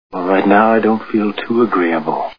High Planes Drifter Movie Sound Bites